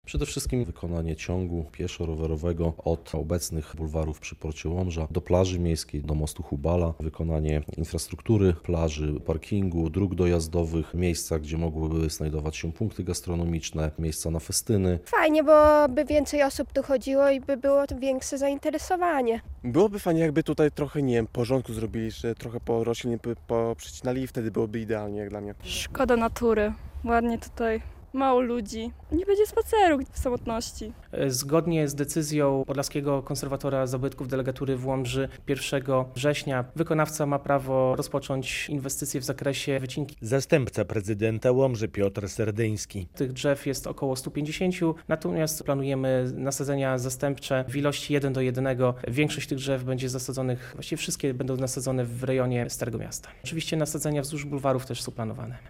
Przygotowanie do prac to wycięcie ponad 150 drzew rosnących nad rzeką, ale zgodnie z pozwoleniem na ich wycinkę nasadzimy tyle samo nowych w starej części miasta, a same bulwary także będą miały swoje nasadzenia - obiecuje zastępca prezydenta Łomży Piotr Serdyński.